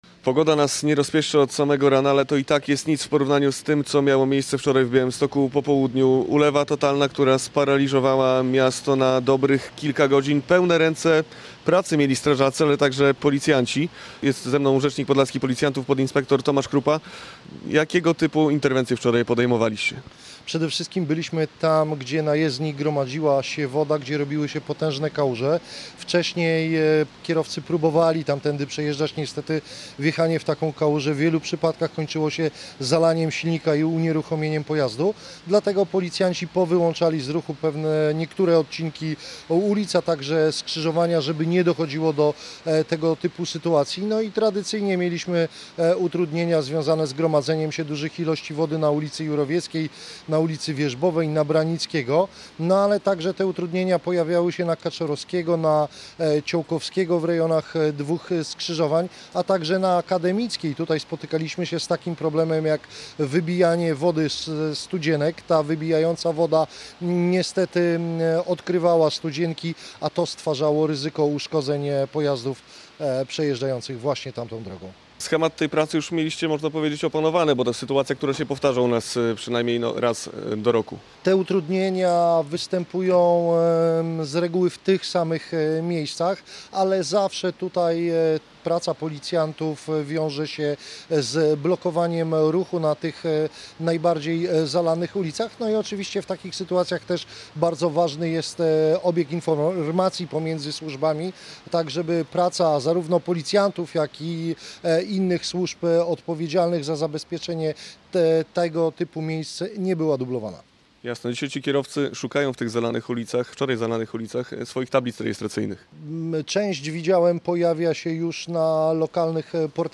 rozmawia